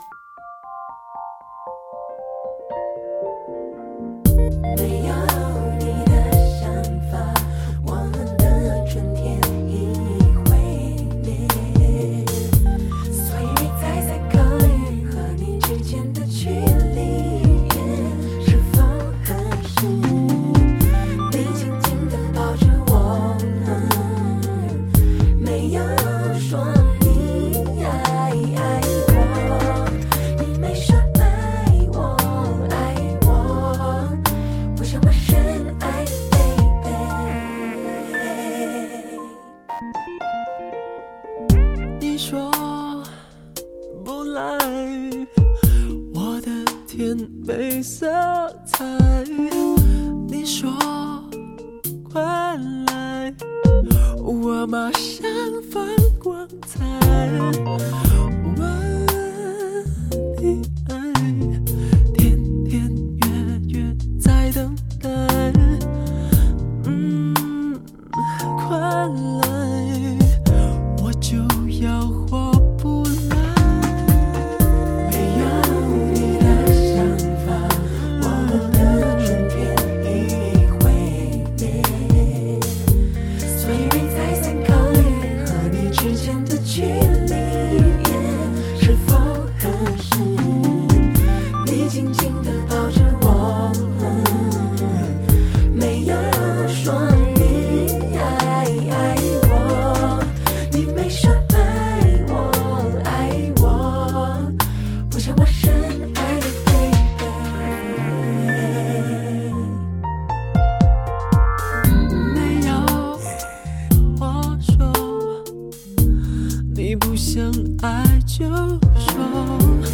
融合Pop、Jazz、Trip-Hop、Gospel Music等多种元素于一身